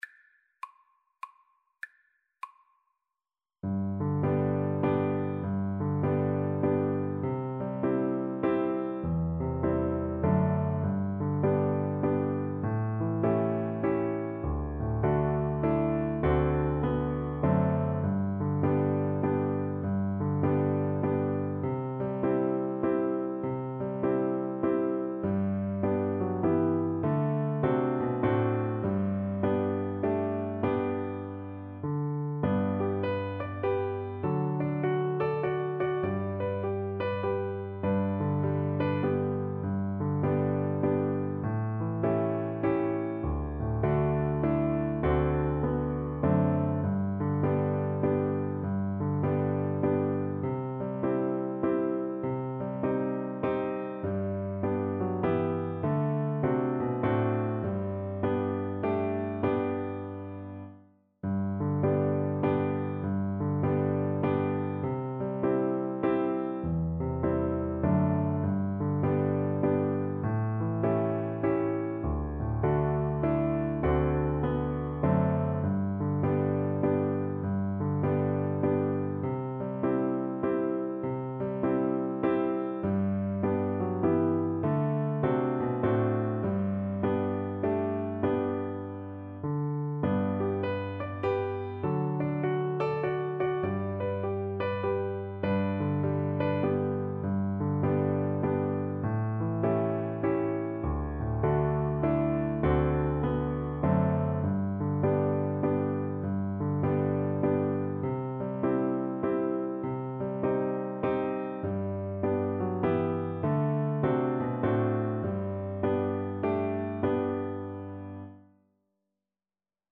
Cello
Home on the Range is a classic western song, sometimes called the "unofficial anthem" of the American West.
3/4 (View more 3/4 Music)
G major (Sounding Pitch) (View more G major Music for Cello )
With a swing = 100
Traditional (View more Traditional Cello Music)